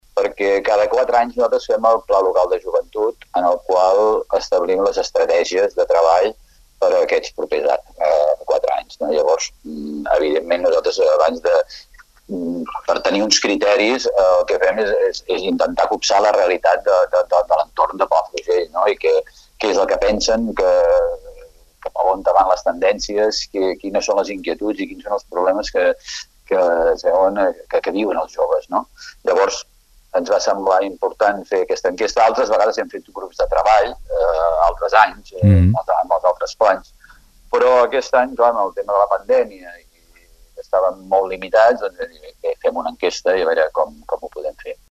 Així ho explicava a Ràdio Palafrugell: